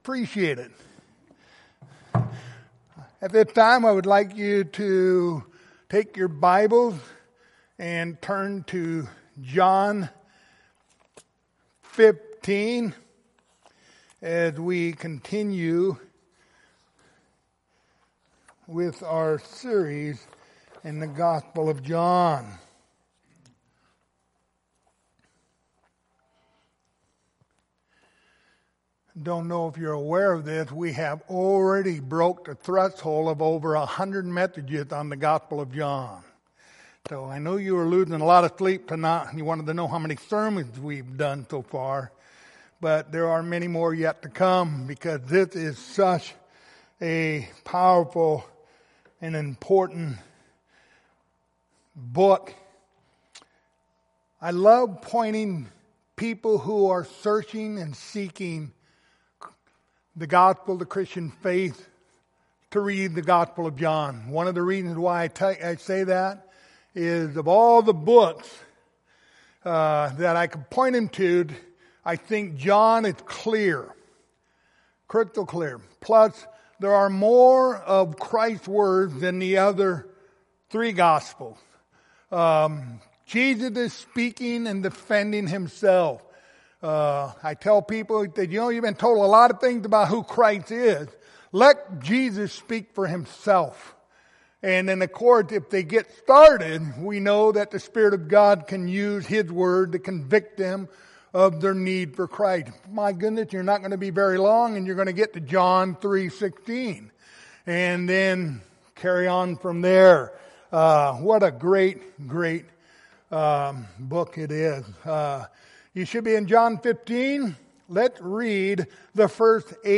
Passage: John 15:1-8 Service Type: Wednesday Evening Topics